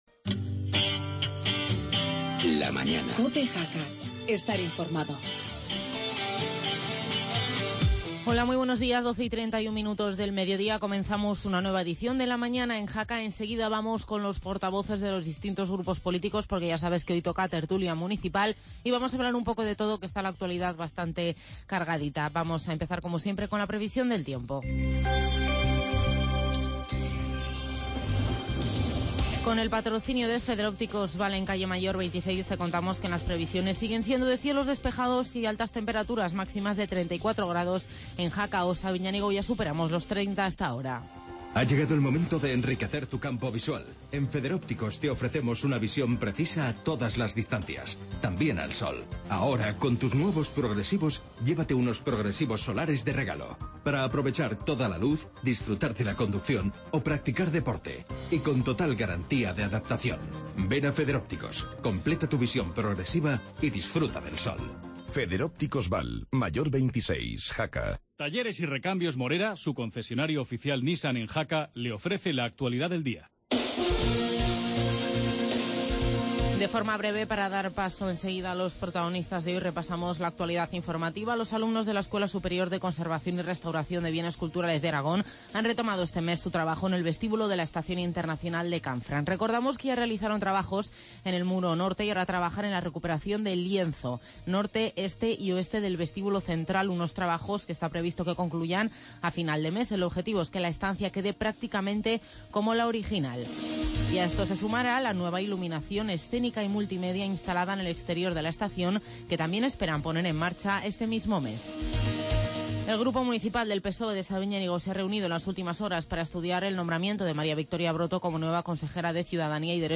AUDIO: Hoy, tertulia municipal con PP, PSOE, CHA, Cambiar Jaca y Aragón Sí Puede.